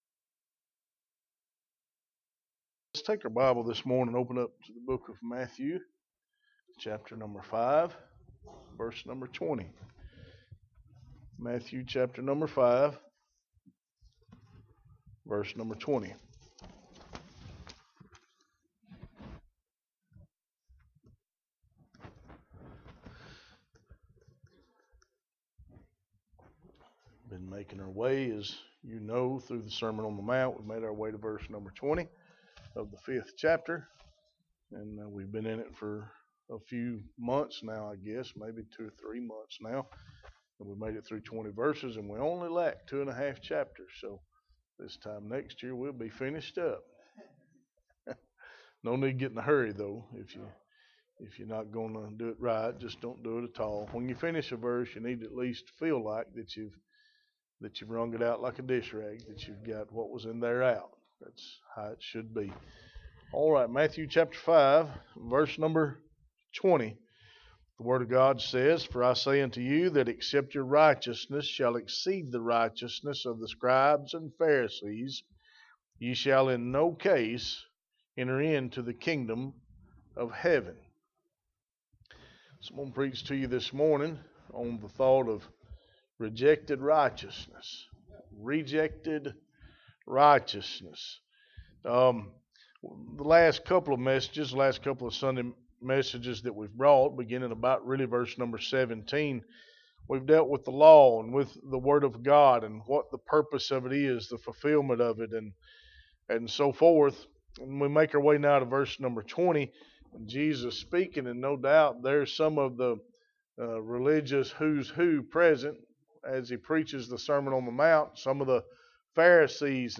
Sermon on the Mount Pt. 14 Rejected Righteousness – Unity Baptist Church